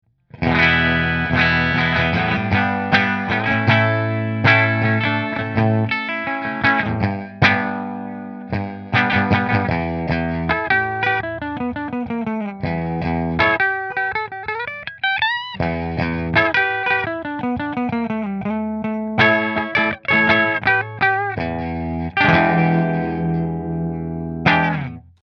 Master Guitars LPM Bridge Through Fender